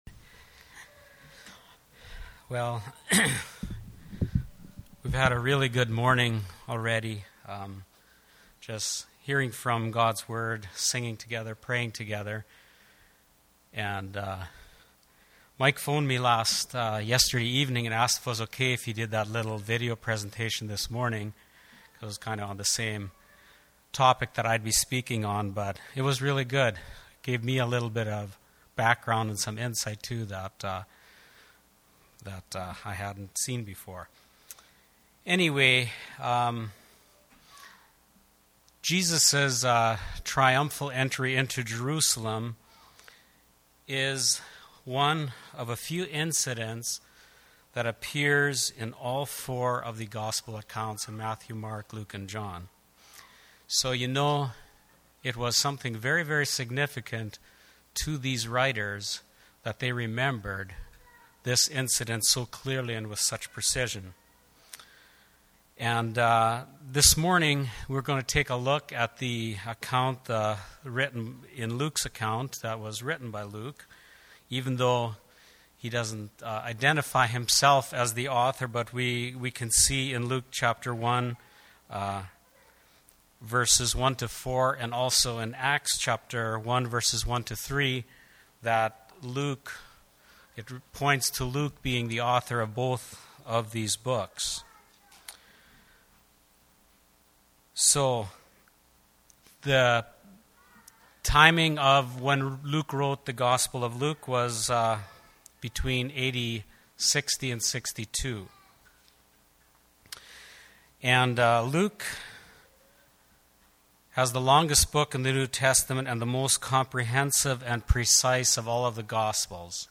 Passage: Luke 19:29-44 Service Type: Sunday Morning « Godly Men Needed